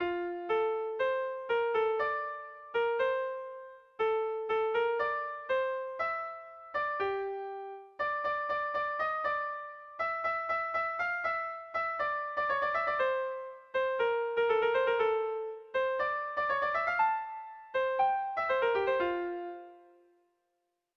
Air de bertsos - Voir fiche   Pour savoir plus sur cette section
Irrizkoa
ABDE